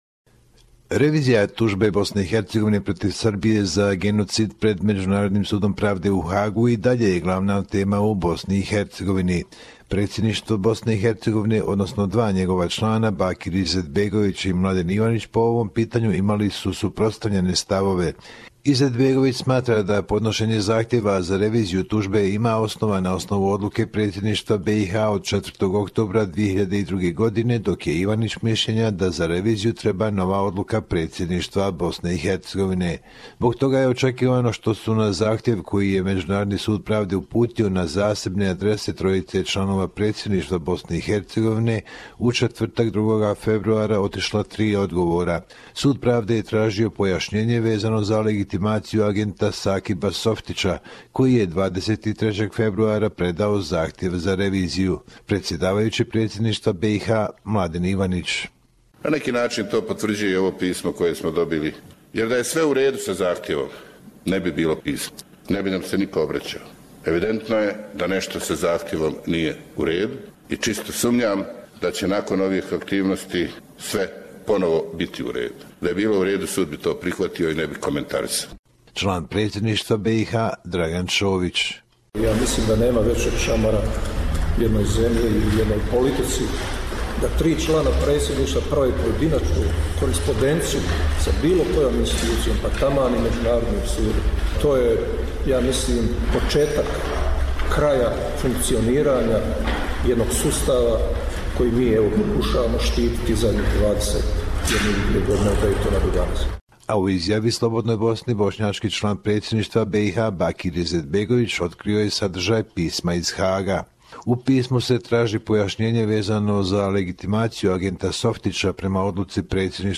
The International Court of Justice sent three letters to each member of the Presidency of Bosnia and Hezegovina. Report from Bosnia and Herzegovina.